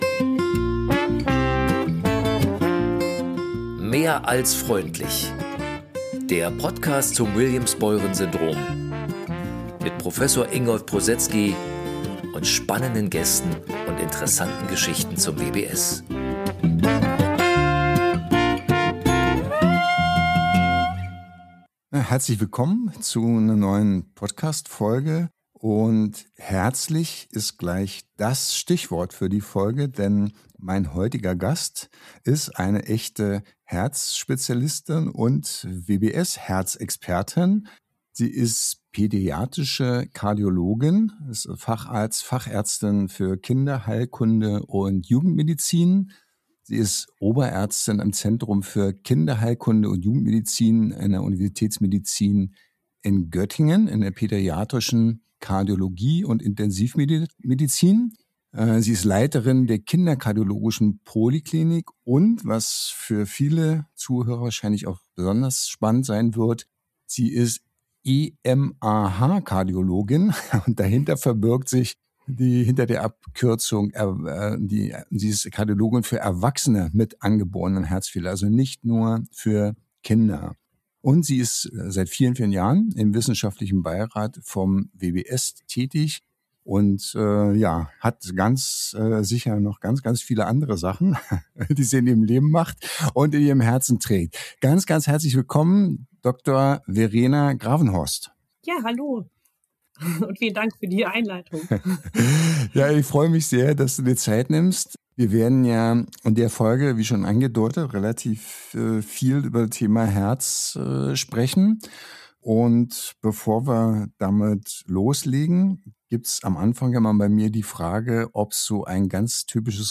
Kardiologin